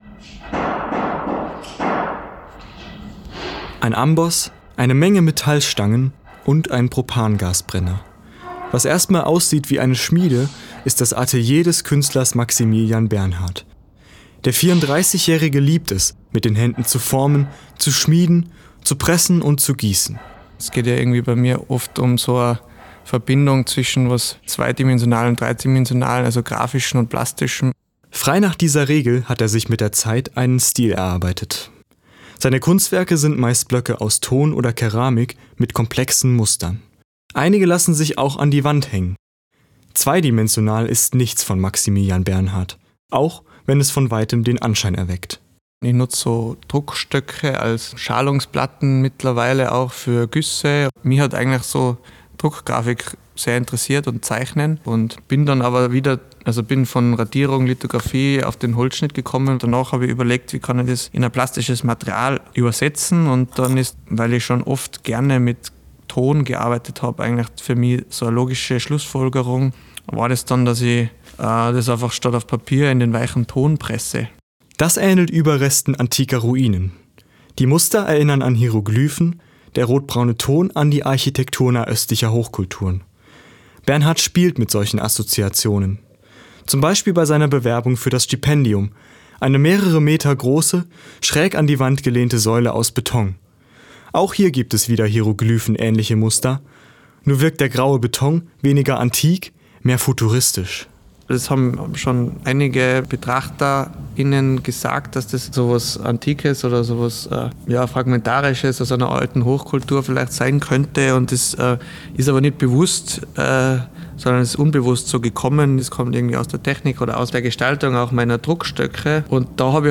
Ich habe ihn in seinem Atelier besucht - und ihn gefragt, was es mit seiner Kunst auf sich hat.